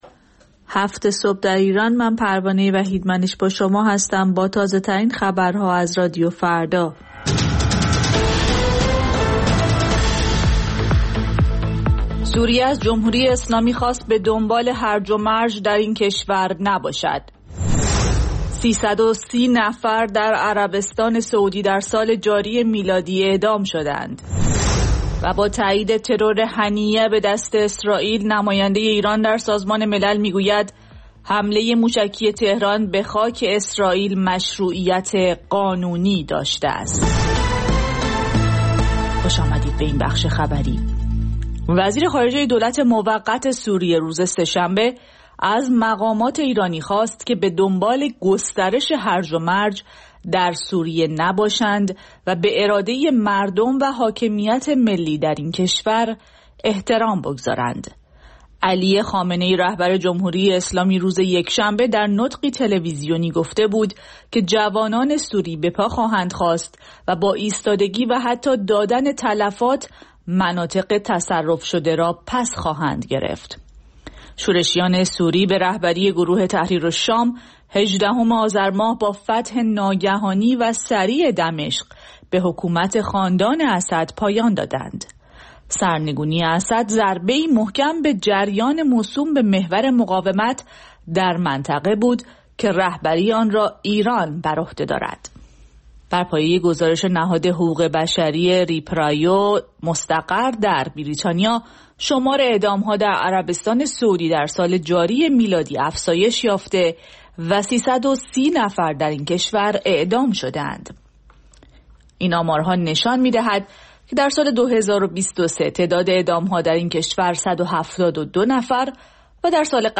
سرخط خبرها ۷:۰۰